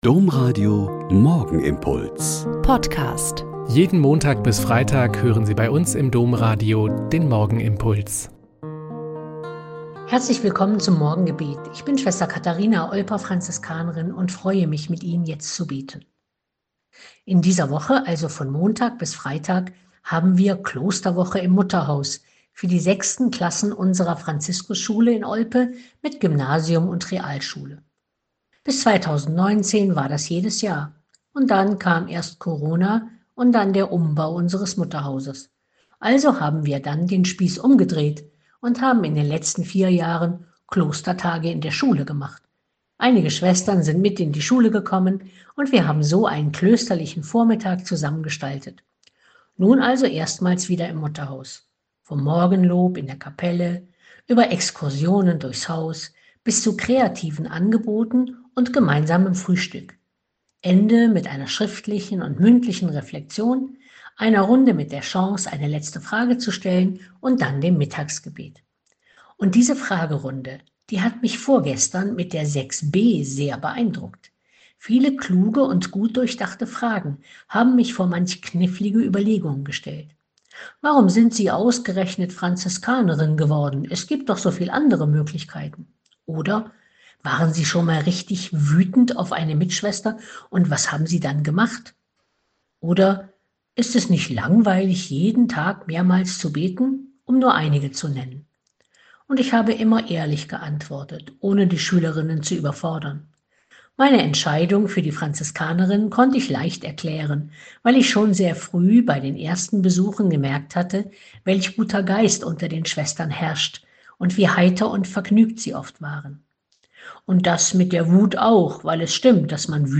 Joh 16,23b-28 - Gespräch